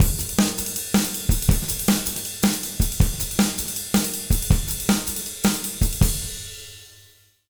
160JUNGLE1-L.wav